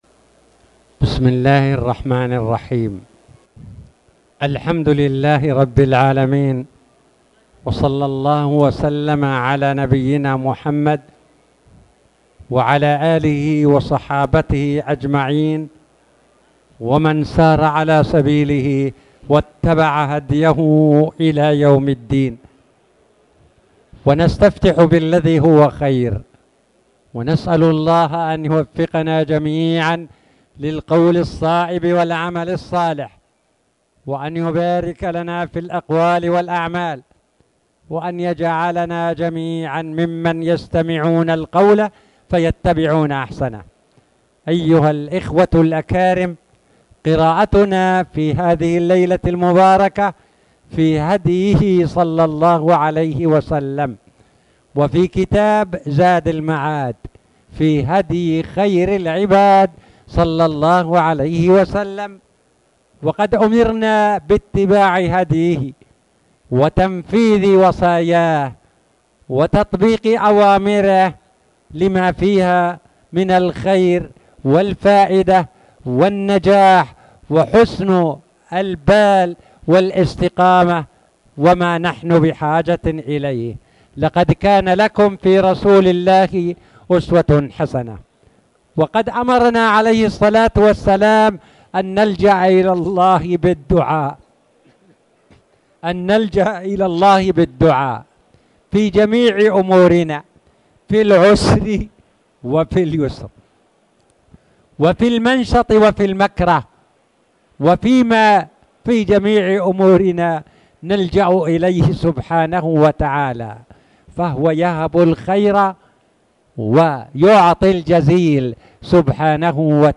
تاريخ النشر ٢ رجب ١٤٣٨ هـ المكان: المسجد الحرام الشيخ